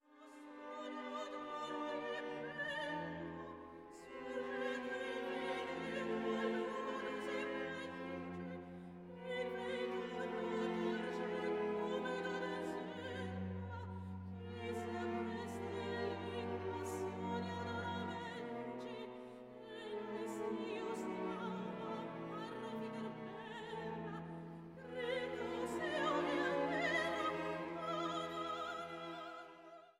japanischen Sopranistin